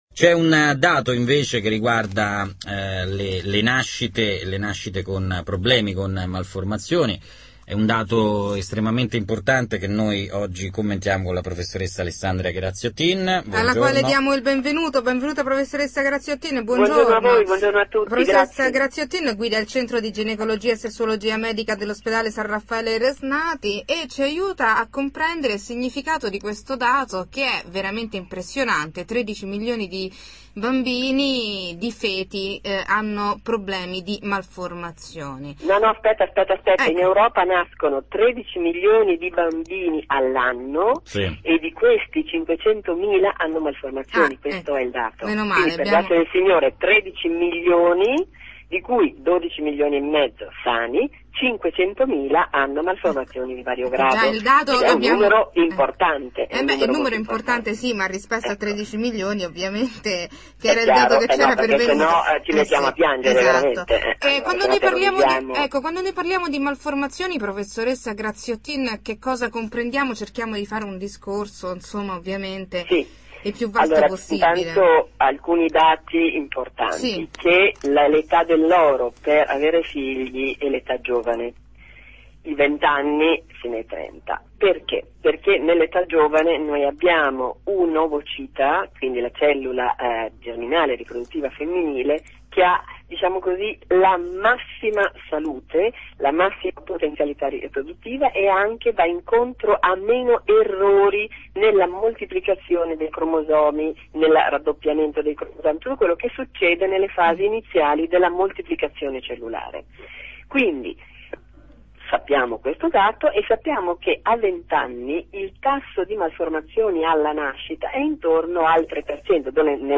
Intervista alla Prof.ssa Alessandra Graziottin